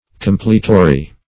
Meaning of completory. completory synonyms, pronunciation, spelling and more from Free Dictionary.
Search Result for " completory" : The Collaborative International Dictionary of English v.0.48: Completory \Com"ple*to"ry\ (? or ?), n. [L. completorium.]